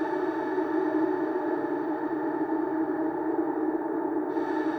Index of /musicradar/sparse-soundscape-samples/Creep Vox Loops
SS_CreepVoxLoopB-08.wav